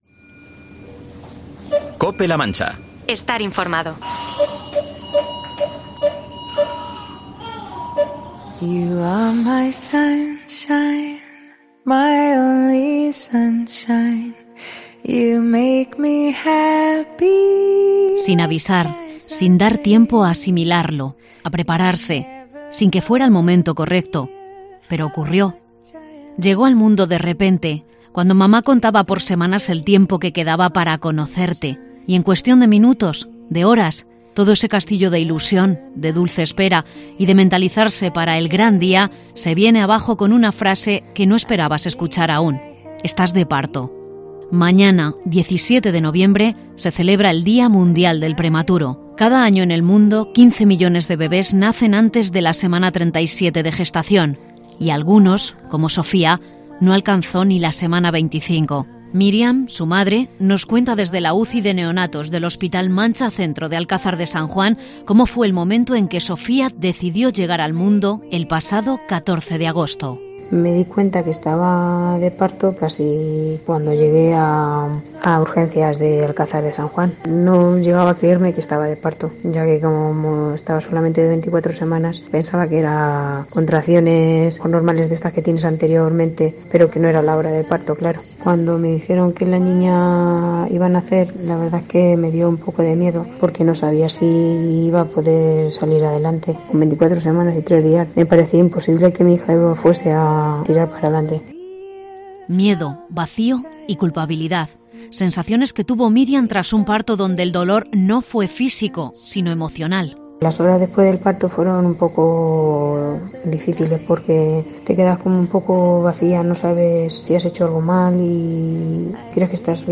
Día Mundial del Prematuro - Reportaje